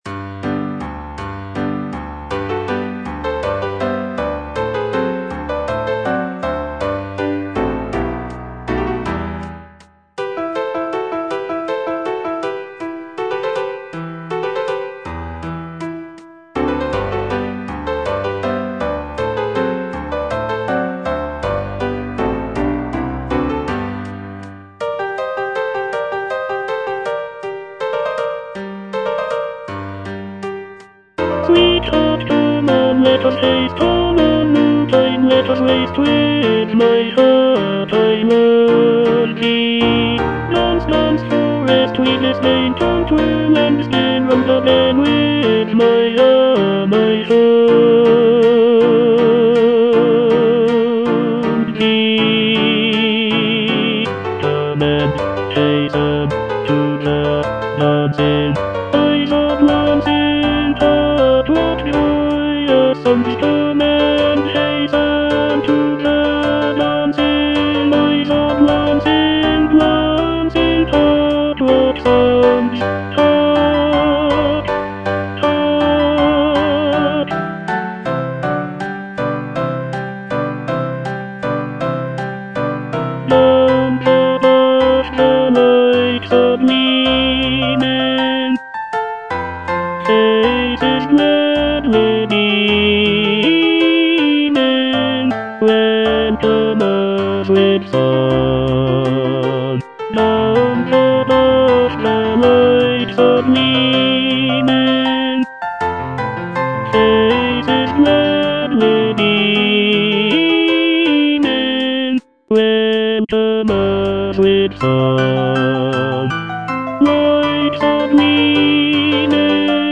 E. ELGAR - FROM THE BAVARIAN HIGHLANDS The dance (tenor I) (Voice with metronome) Ads stop: auto-stop